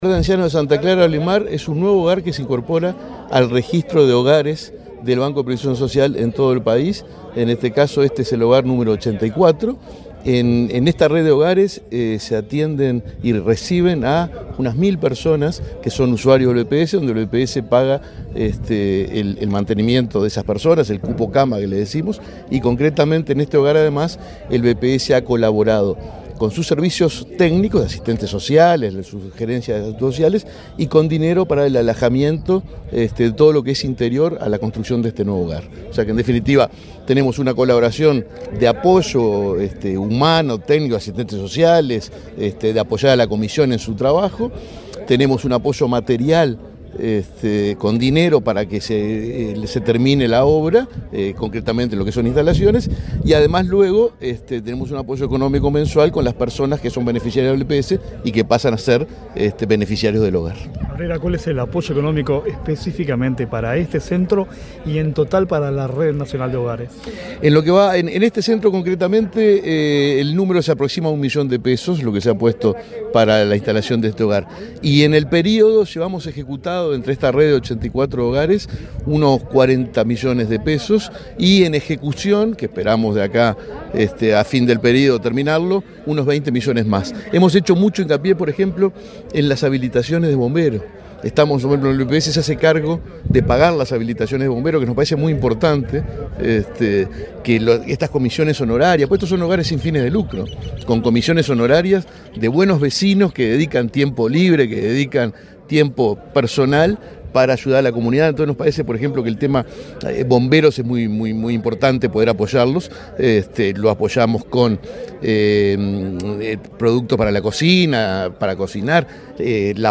Entrevista al presidente del BPS, Alfredo Cabrera
El presidente del Banco de Previsión Social (BPS), Alfredo Cabrera, dialogó con Comunicación Presidencial en Treinta y Tres, antes de participar en la